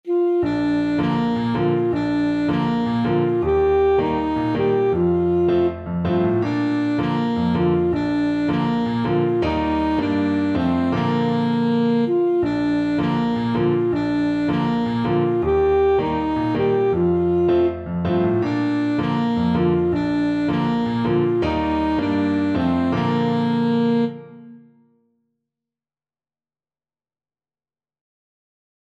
Alto Saxophone
2/4 (View more 2/4 Music)
Bb major (Sounding Pitch) G major (Alto Saxophone in Eb) (View more Bb major Music for Saxophone )
Steadily =80
Traditional (View more Traditional Saxophone Music)
Cameroonian
good_morning_ASAX.mp3